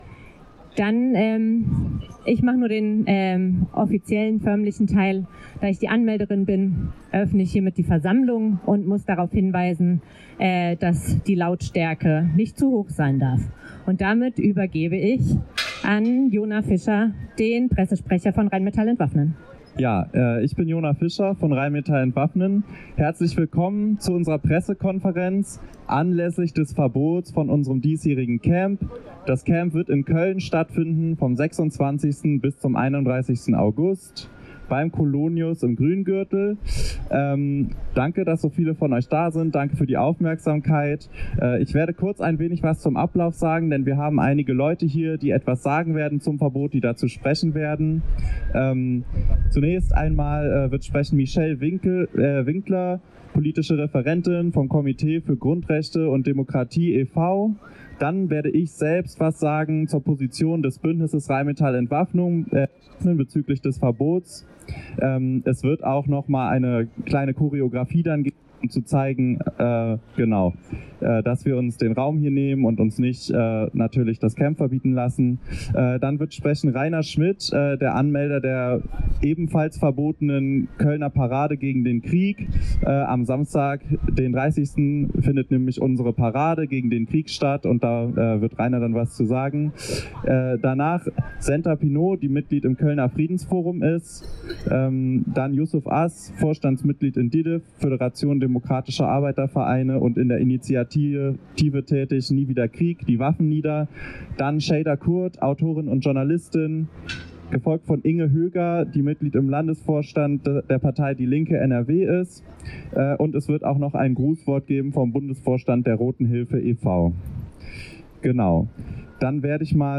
Pressekonferenz in Köln: Bündnis „Rheinmetall Entwaffnen“ protestiert gegen Camp-Verbot – radio nordpol
Am Freitag, den 15.08.2025, lud das antimilitaristische Bündnis „Rheinmetall Entwaffnen“ zu einer Pressekonferenz auf der Kölner Domplatte ein.
Gegen das Verbot legt das Bündnis energischen Widerspruch ein. Radio Nordpol dokumentiert die Redebeiträge von der Pressekonferenz: